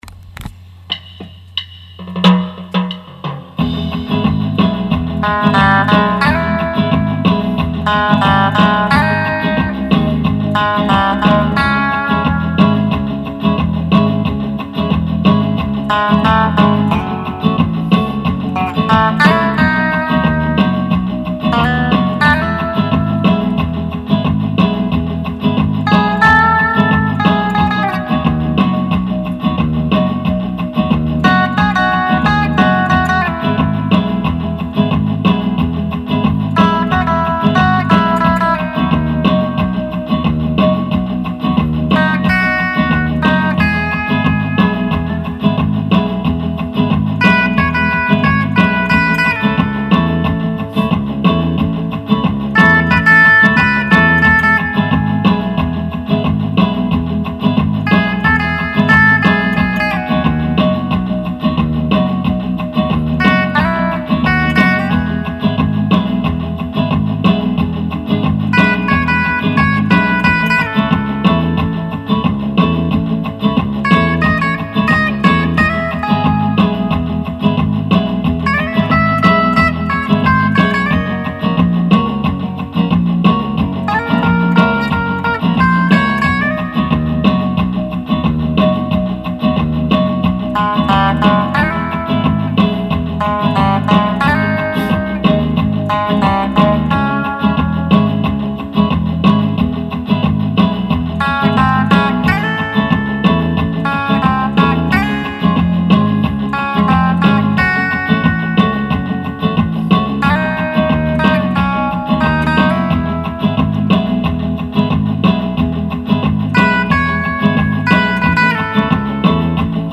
Pop Rumba